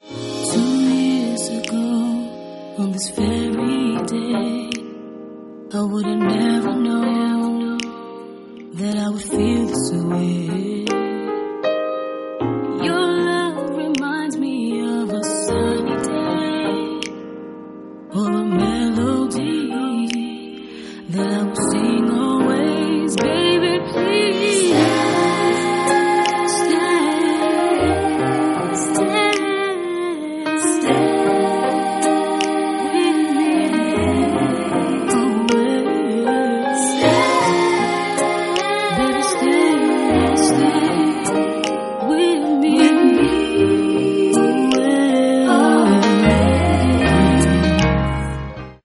Sorties R'nB
quelques ballades classique dans le genre